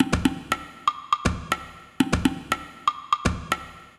120_perc_1.wav